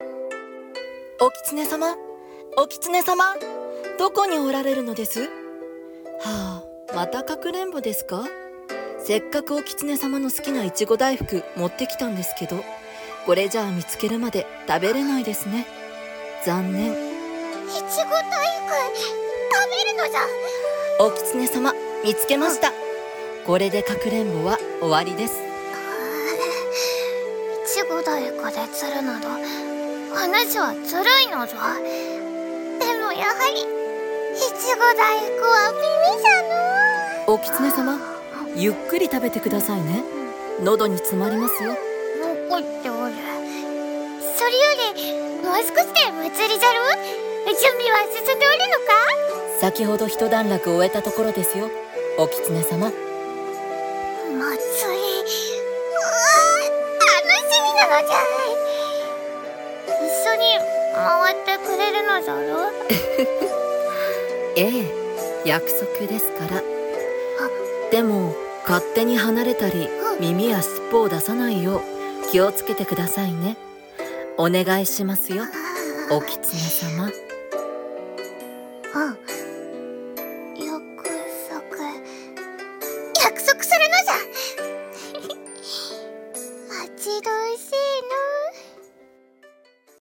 【声劇】お狐様とお祭りでの約束事【掛け合い】